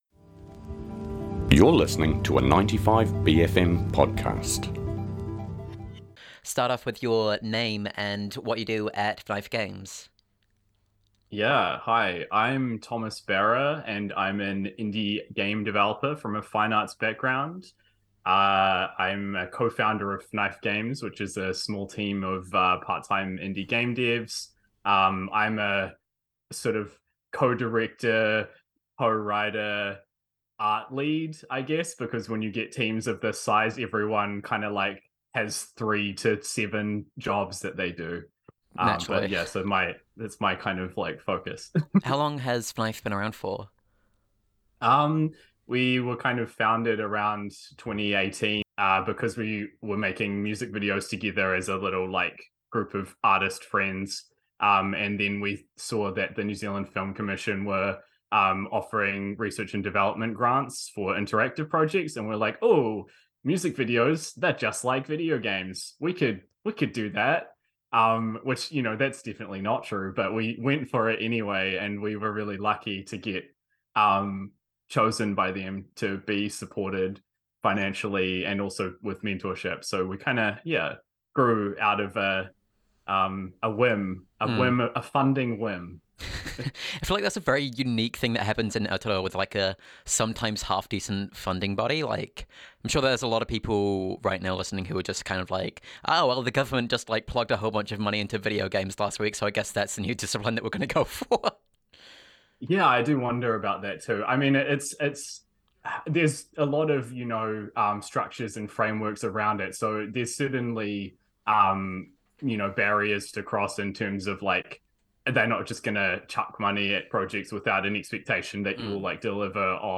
This is the extended cut, where we also talk about Fnife Games' other projects and other stuff.